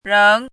“仍”读音
réng
仍字注音：ㄖㄥˊ
国际音标：ʐəŋ˧˥
réng.mp3